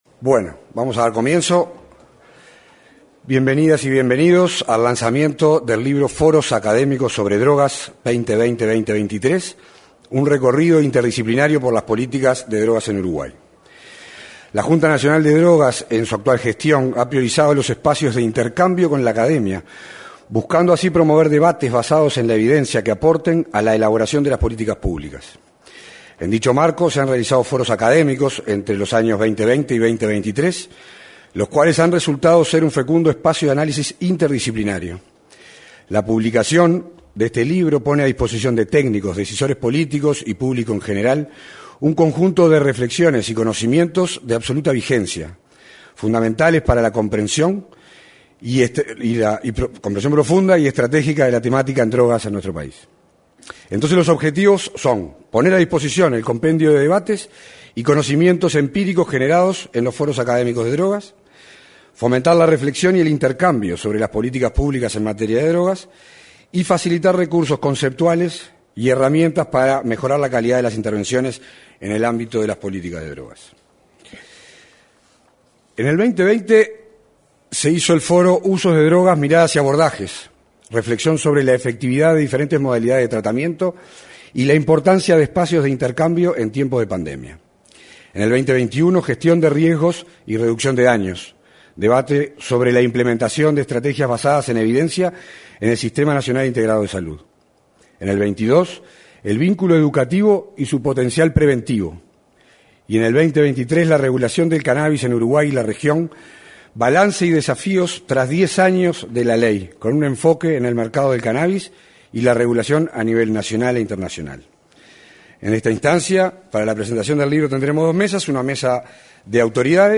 En la oportunidad se expresaron, el secretario de la Presidencia de la República y presidente de la Junta Nacional de Drogas, Rodrigo Ferrés, y el subsecretario del Ministerio del Interior, Pablo Abdala.